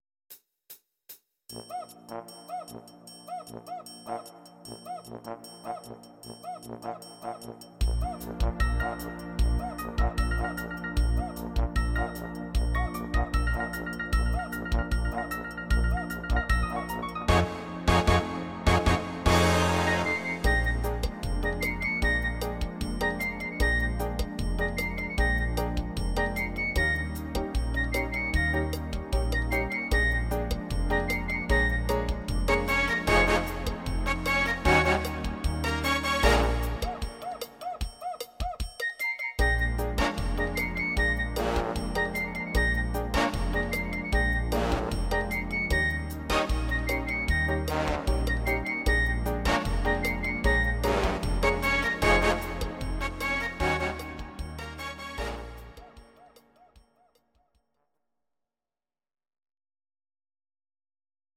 (instr.)